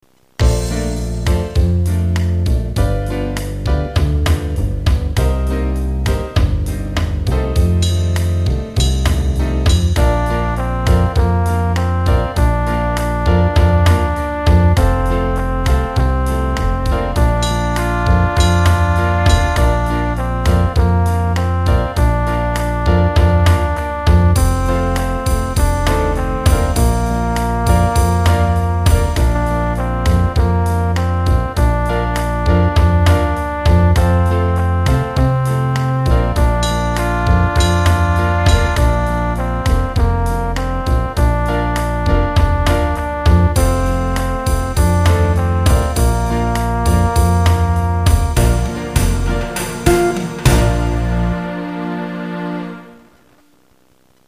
Mary had a little Lamb (slow) 2 copy.mp3